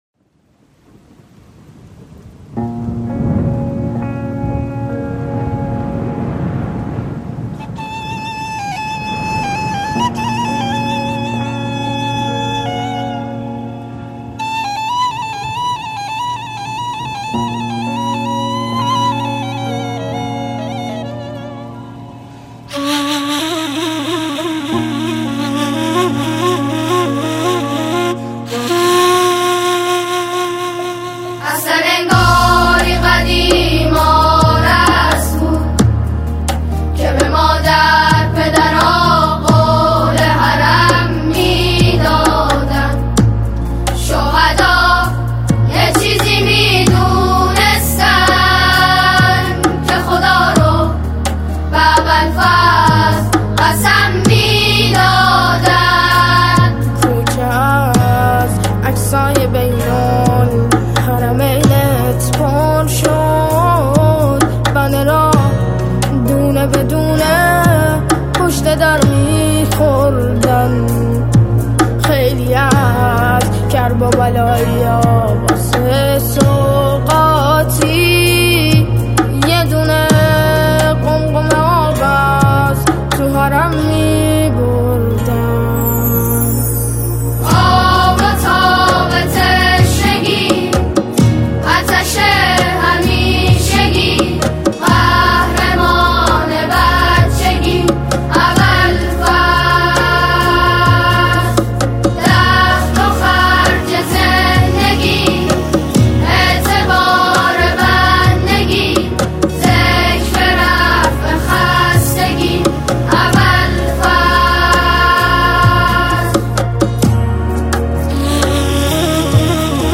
سرودهای امام حسین علیه السلام